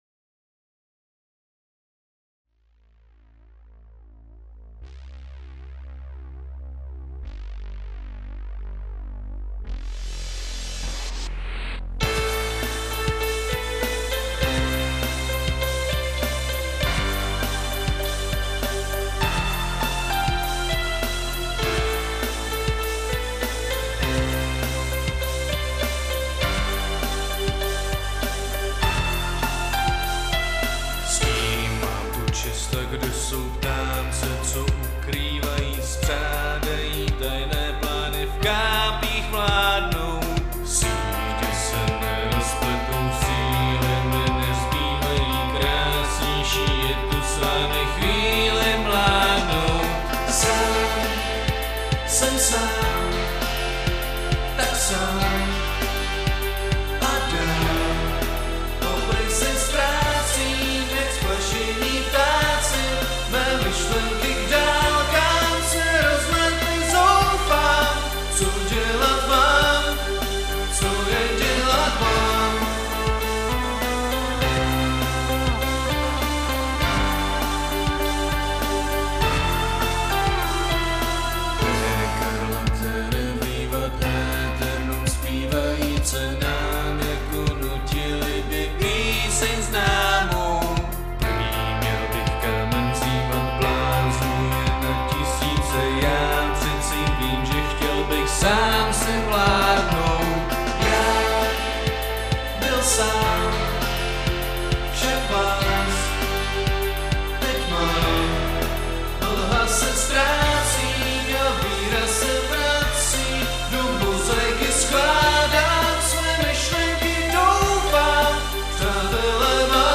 433_VIVANT AETERNUM (band_version).mp3